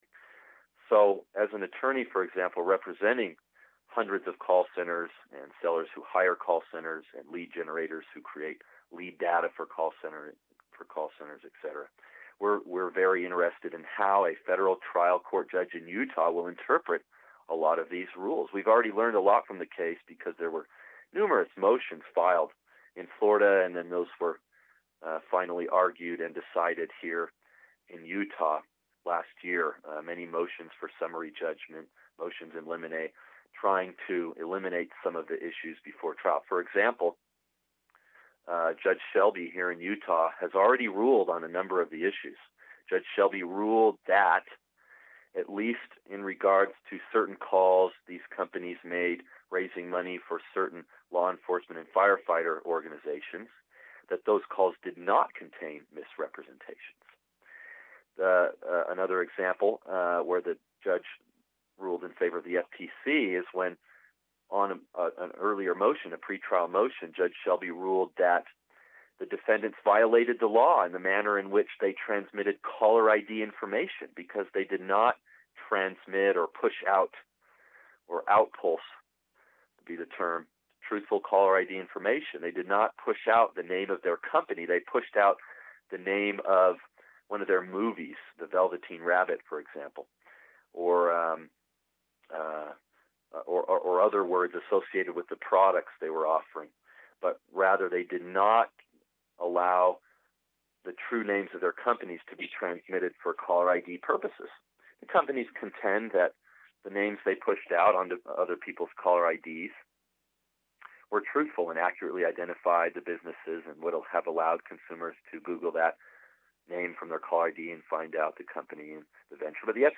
Extended interview: Robocalls, the Do Not Call list, and companies